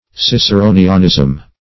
Search Result for " ciceronianism" : The Collaborative International Dictionary of English v.0.48: Ciceronianism \Cic`e*ro"ni*an*ism\, n. Imitation of, or resemblance to, the style or action Cicero; a Ciceronian phrase or expression.
ciceronianism.mp3